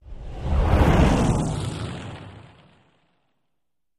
Medium Fireball | Sneak On The Lot